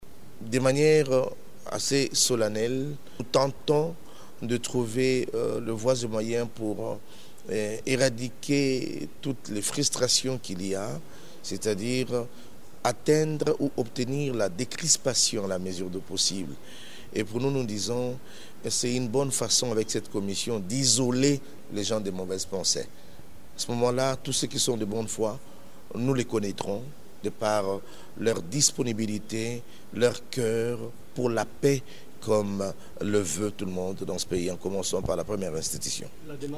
archeveque_dodo_kamba_2-web.mp3